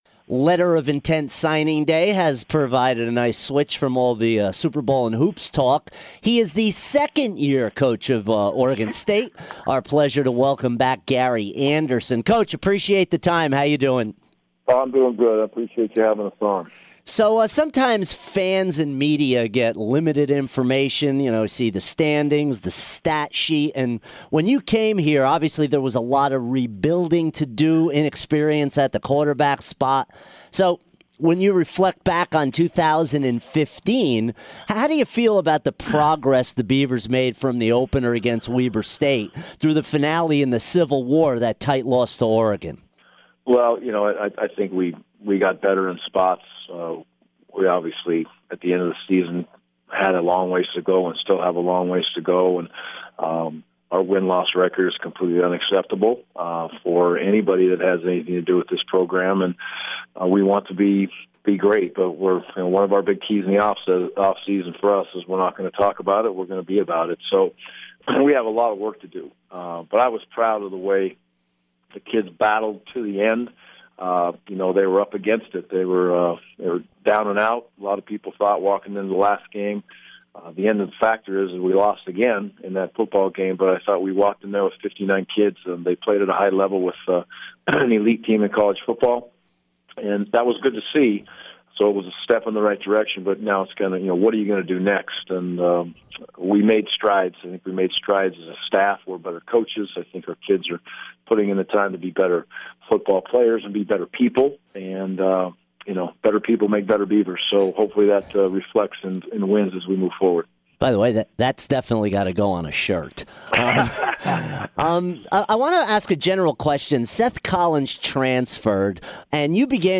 Gary Andersen Interview 2-4-16